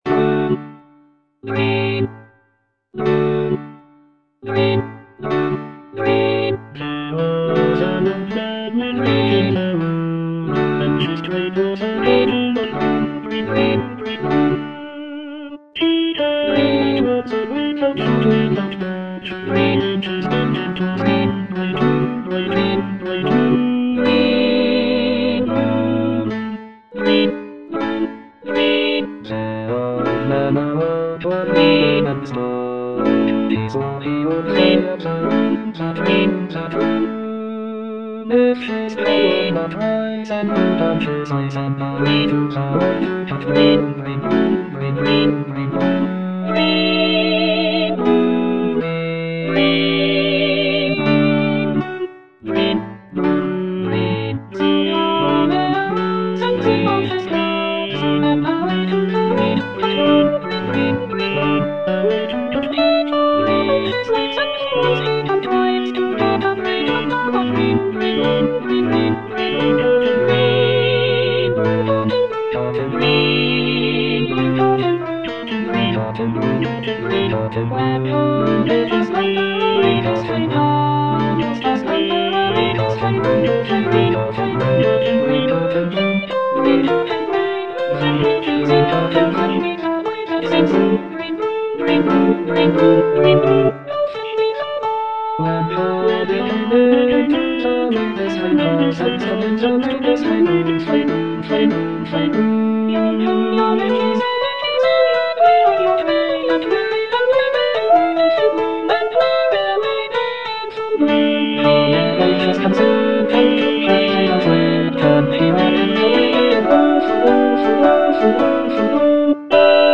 (All voices)
folk song